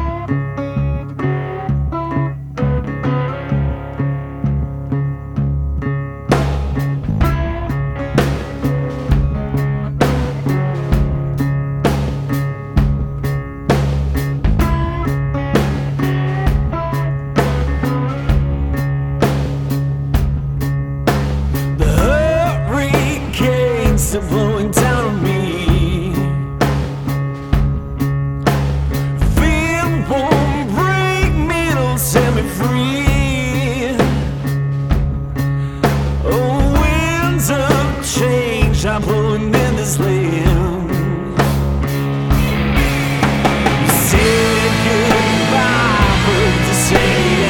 Blues › BluesRock